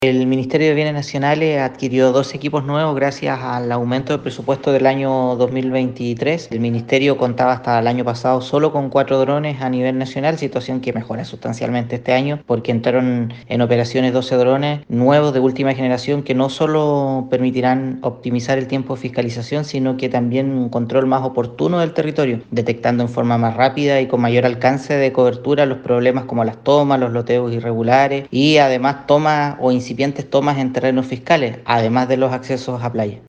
Al respecto, el titular de la cartera en la región, Pablo Joost, se refirió a los resultados de los operativos, señalando que en Castro se detectó un vehículo que no poseía su documentación vigente y, a su vez, la conductora no portaba la licencia adecuada para manejar el furgón de transporte de escolares: